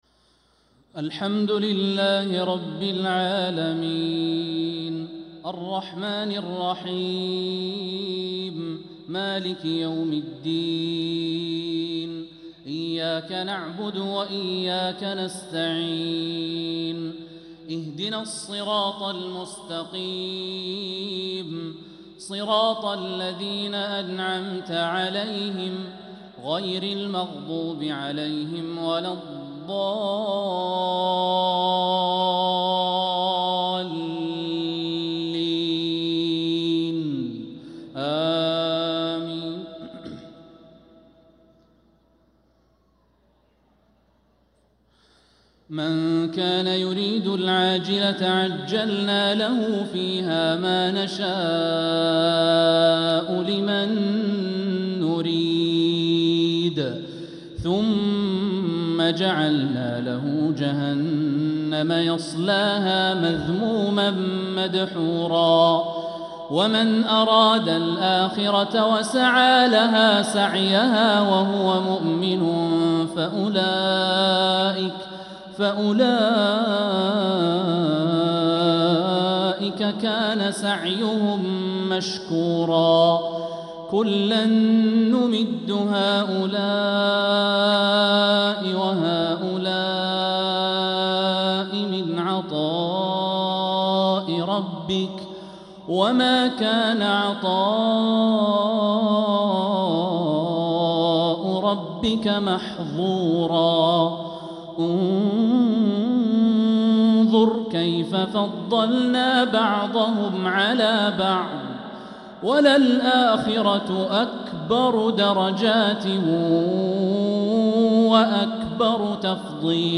عشاء الأحد 5-7-1446هـ من سورة الإسراء 18-25 | isha prayer from Surat Al-Isra 5-1-2025 🎙 > 1446 🕋 > الفروض - تلاوات الحرمين